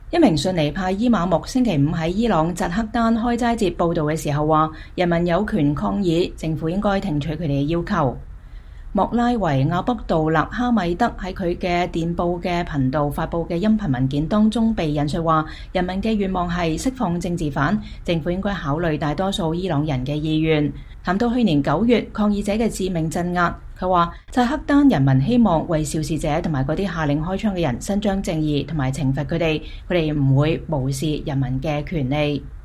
一名遜尼派伊瑪目星期五在伊朗扎黑丹開齋節佈道時說，人民有權抗議，政府應該聽取他們的要求。